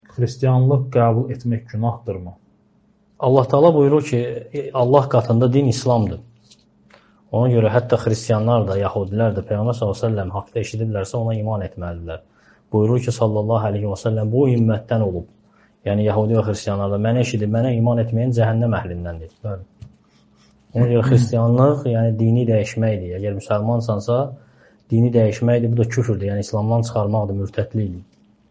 Sual-cavab